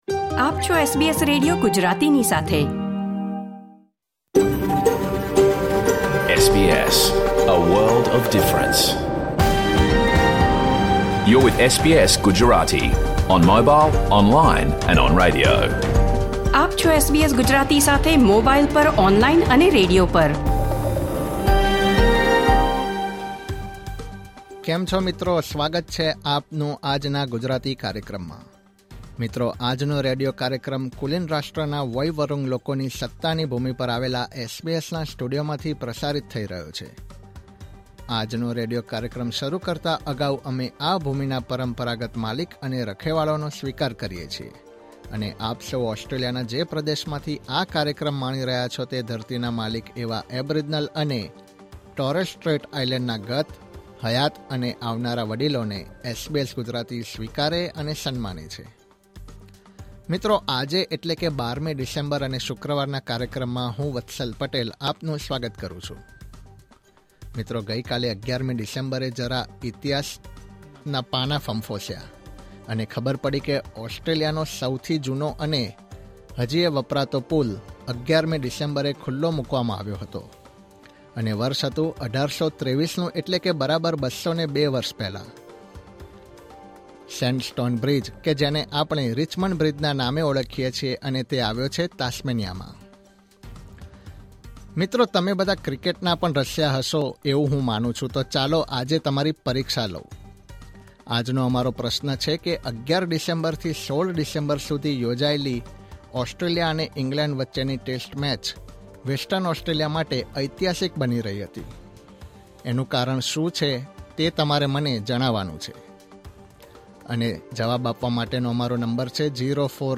Catch the full episode of SBS Gujarati radio program: 12 December 2025